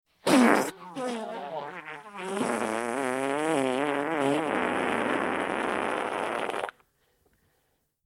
Sound Effects
Very Wet Fart New